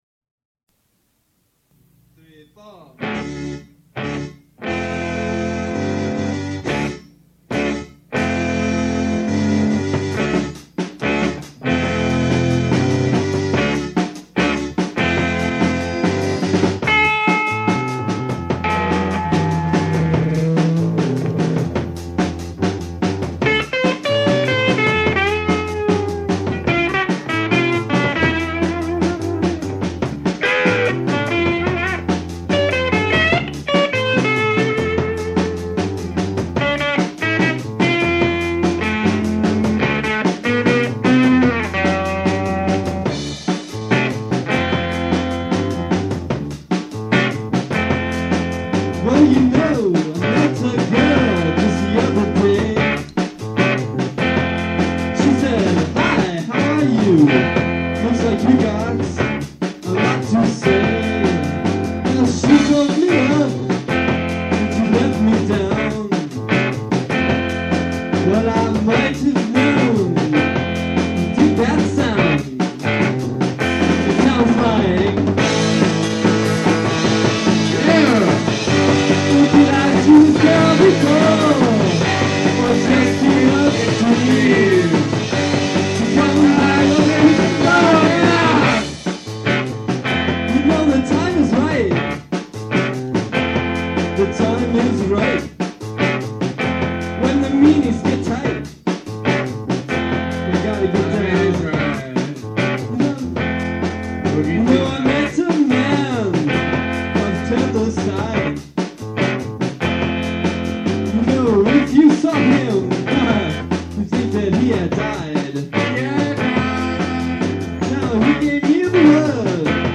the extra eighth note in the opening bars
the vocal over lead guitar part
the ending bars in E Flat Major